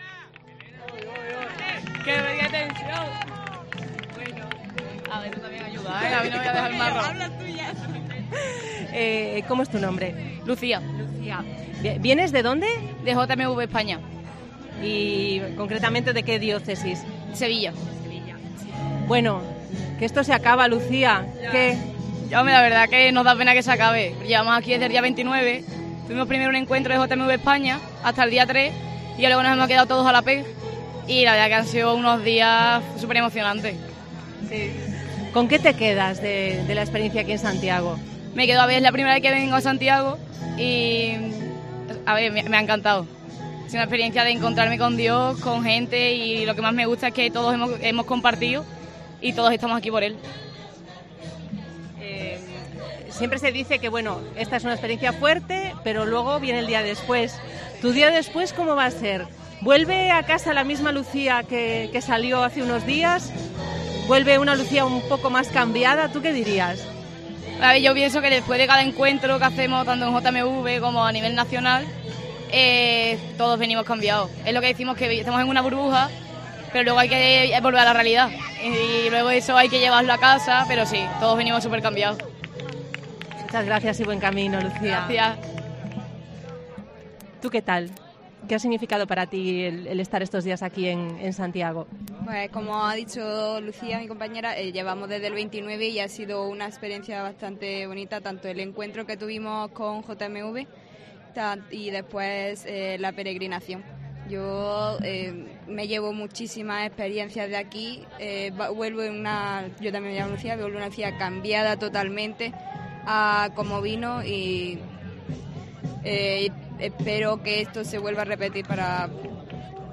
Dos jóvenes sevillanas contaron a Cope Santiago cómo les ha cambiado la experiencia de la PEJ 22